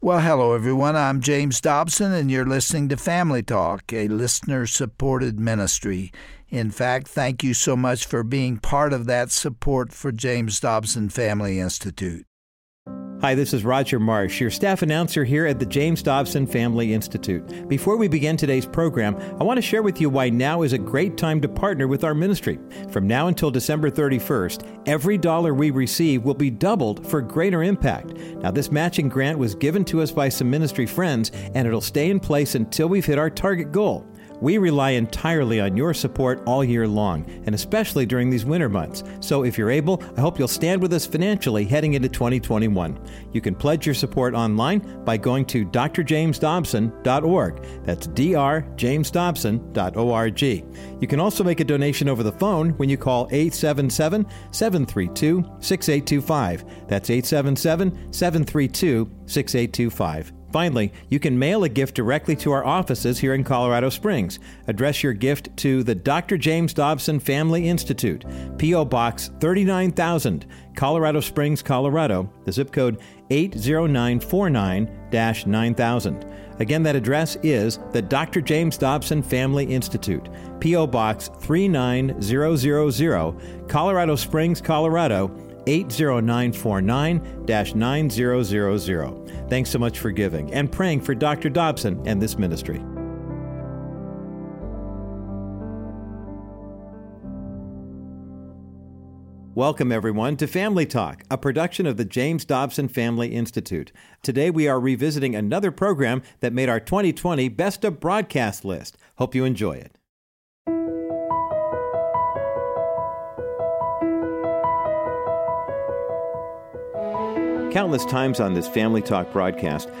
On this "2020 Best of Broadcast" edition, you'll hear a speech Dr. Dobson gave which focused on the heartfelt memories of his father. He began by highlighting the importance of a masculine influence on a young boy, and then expressed the most substantial lessons he learned from his dad.